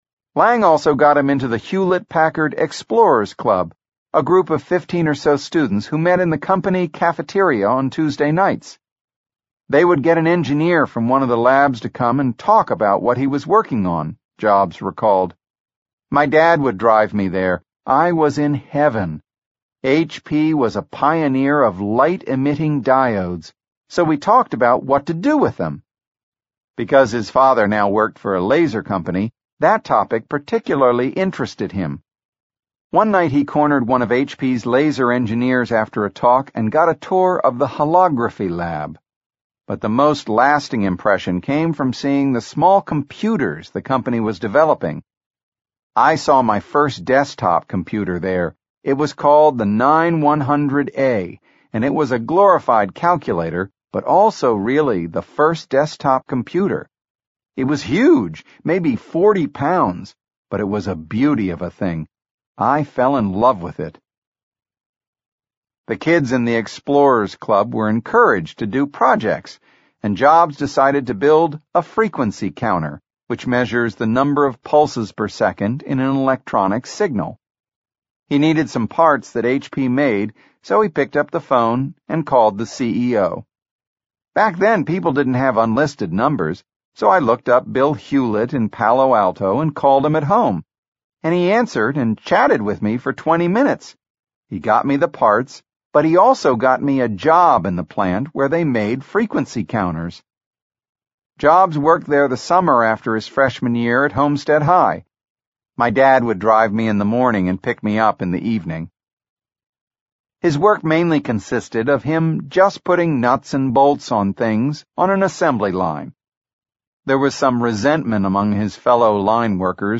在线英语听力室乔布斯传 第17期:上学(5)的听力文件下载,《乔布斯传》双语有声读物栏目，通过英语音频MP3和中英双语字幕，来帮助英语学习者提高英语听说能力。
本栏目纯正的英语发音，以及完整的传记内容，详细描述了乔布斯的一生，是学习英语的必备材料。